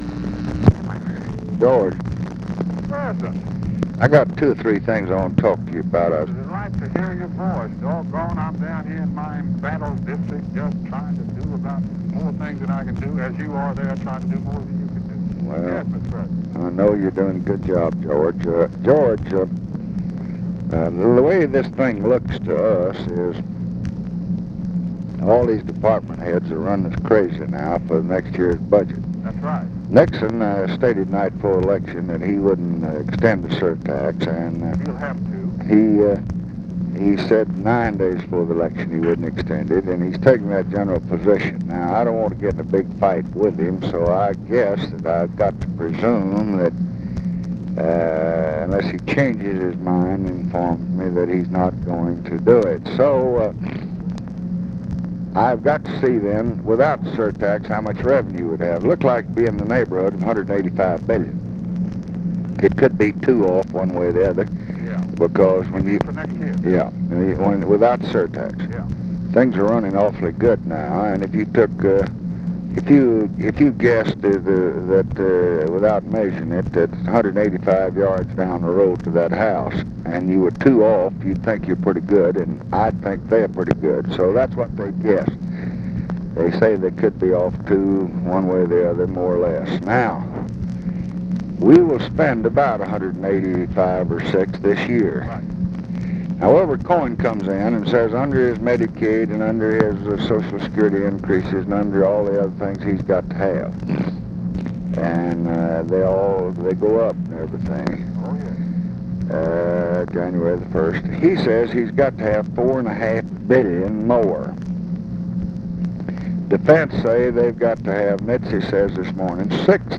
Conversation with GEORGE MAHON, November 16, 1968
Secret White House Tapes